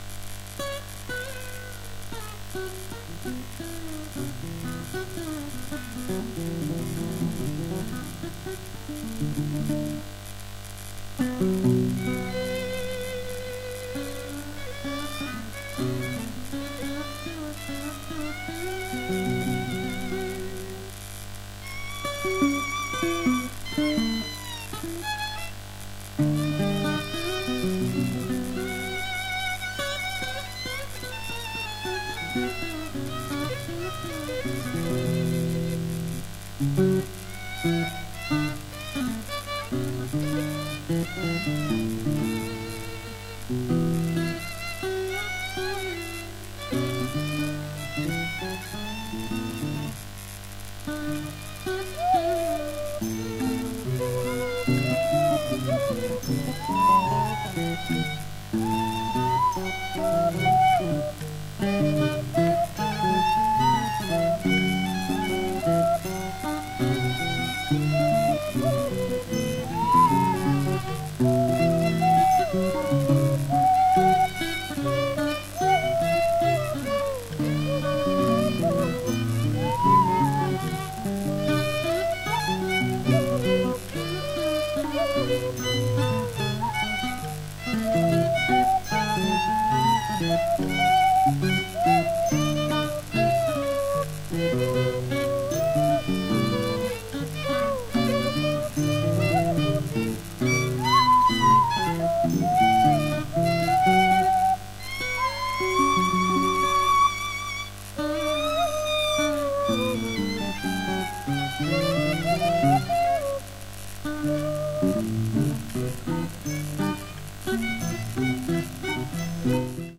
西洋からミャンマーへ沢山の弦楽器が流れてきた1800年代をモチーフにしたミャンマー音楽集！
※レコードの試聴はノイズが入ります。